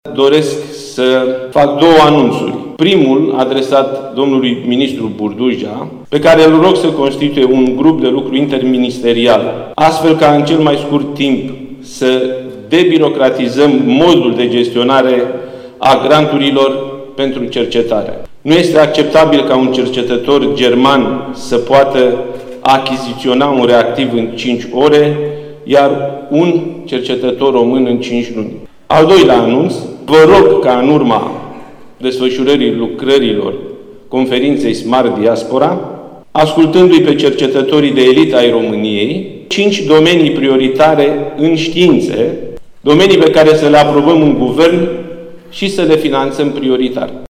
Prezent la Timișoara, la deschiderea conferinței Smart Diaspora, la care participă sute de cercetărori români din țară și Diaspora, premierul Nicolae Ciucă a mai cerut propunerea a cinci domenii prioritare în științe, care să fie finanțate prioritar.